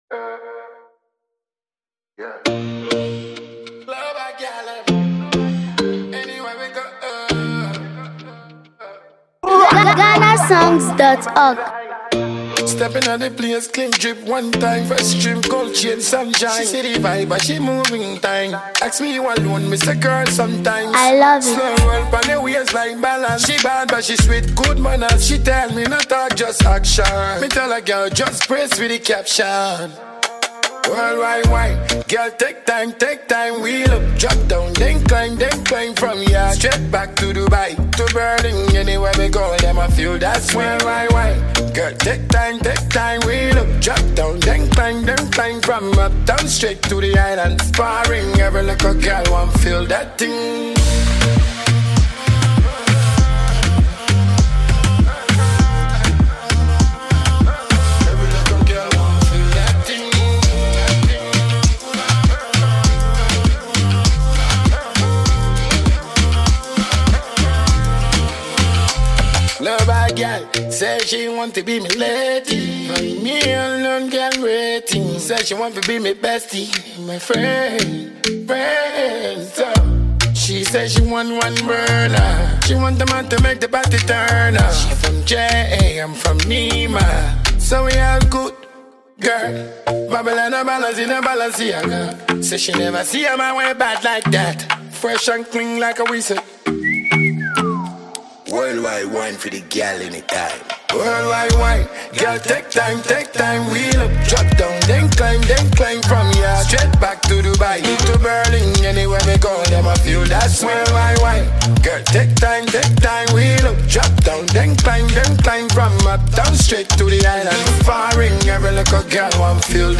energetic anthem